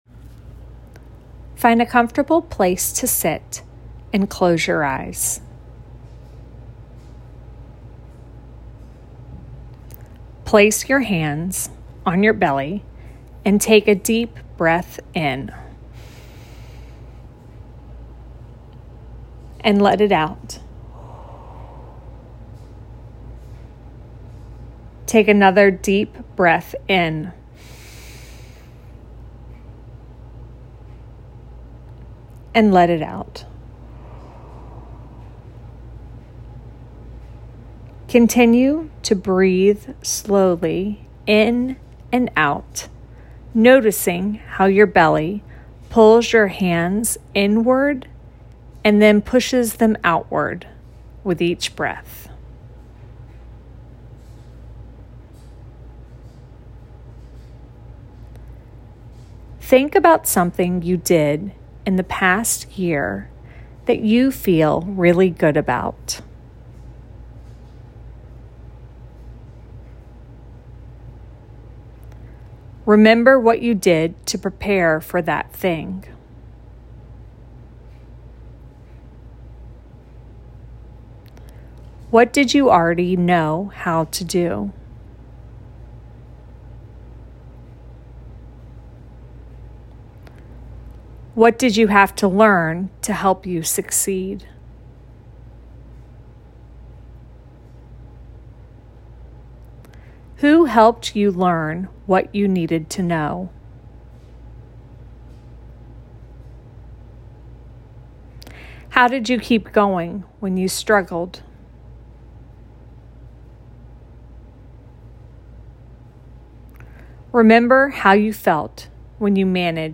A New Year’s Meditation | Real Kids Real Faith